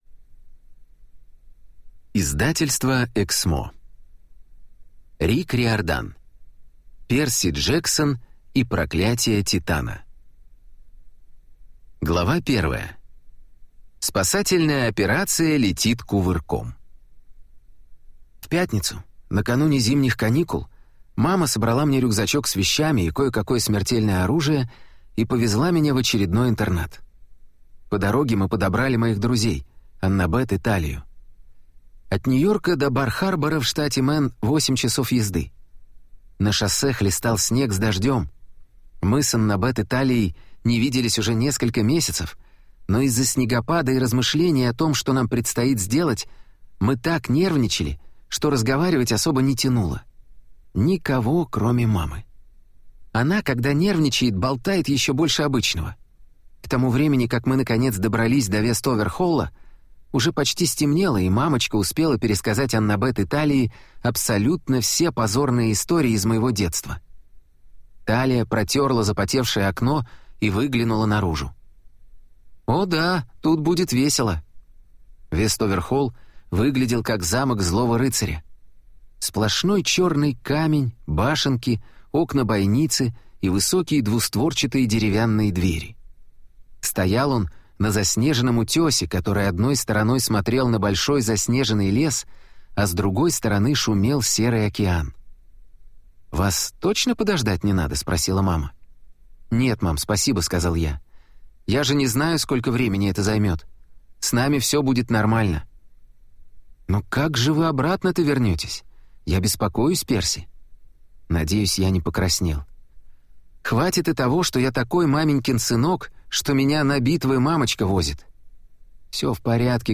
Аудиокнига Перси Джексон и проклятие титана | Библиотека аудиокниг